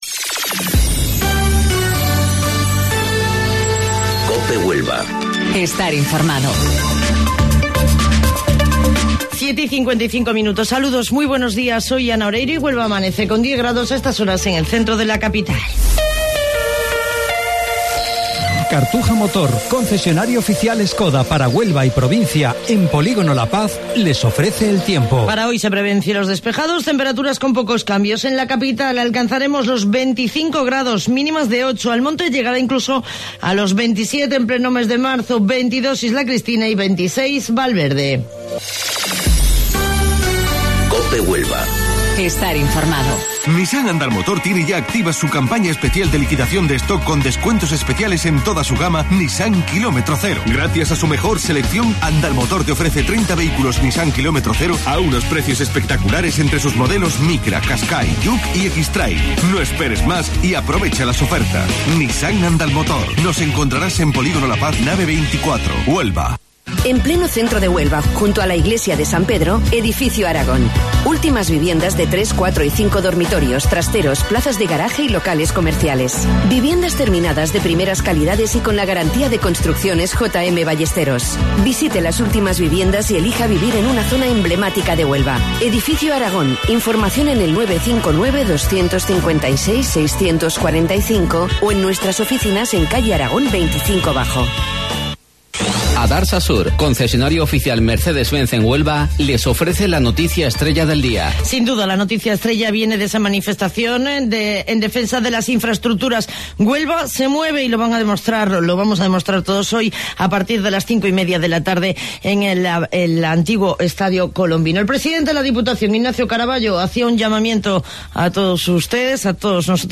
AUDIO: Informativo Local 07:55 del 15 de Marzo